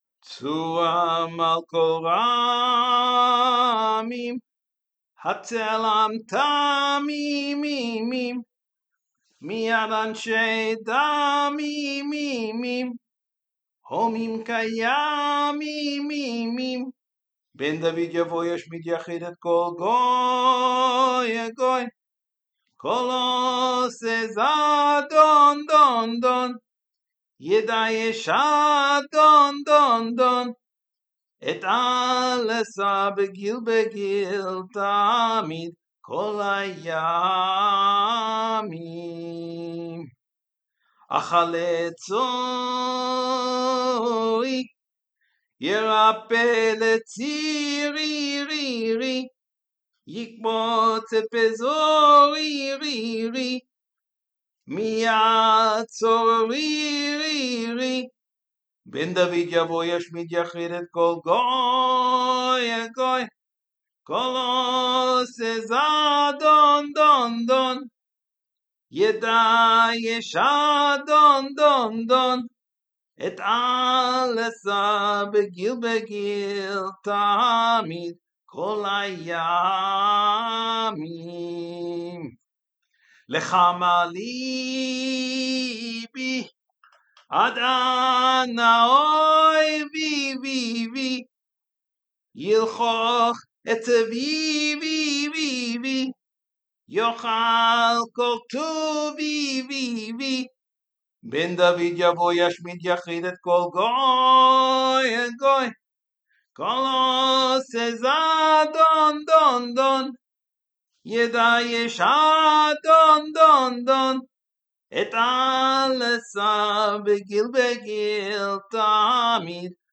Associated with Iraqi tradition.